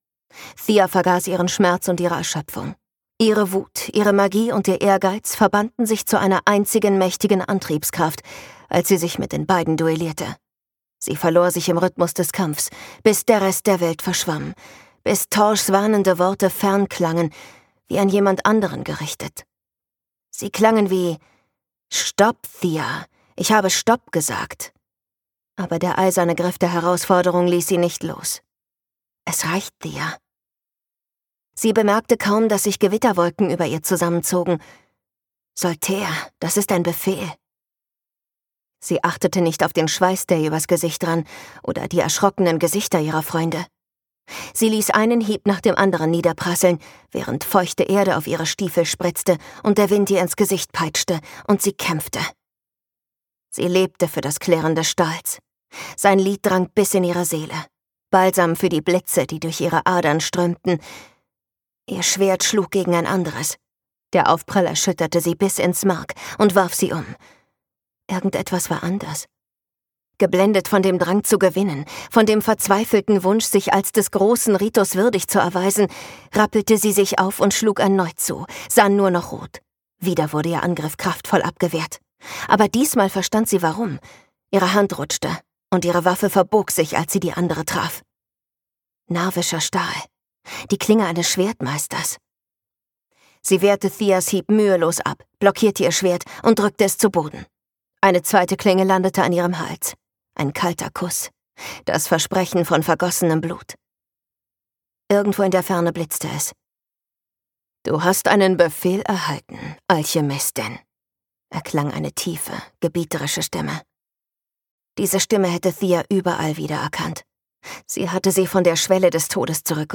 Vows and Ruins - Helen Scheuerer | argon hörbuch
Gekürzt Autorisierte, d.h. von Autor:innen und / oder Verlagen freigegebene, bearbeitete Fassung.